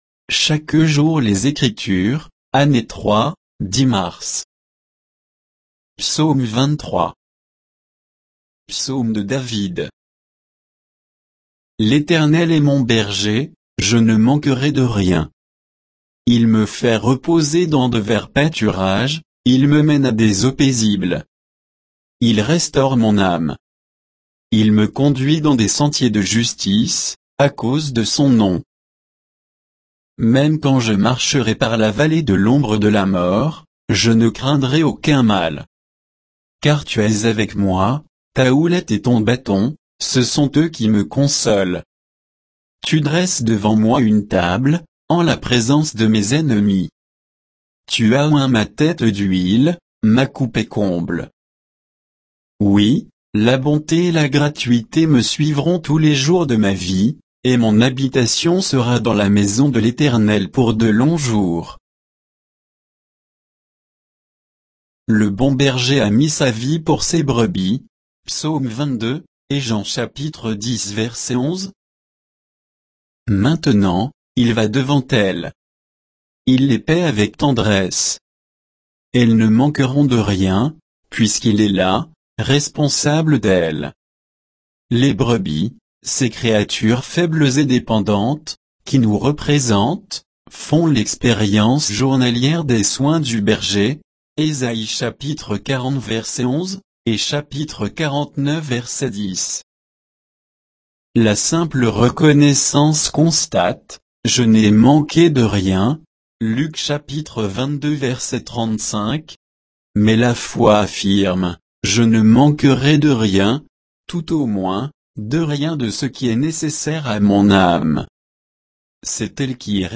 Méditation quoditienne de Chaque jour les Écritures sur Psaume 23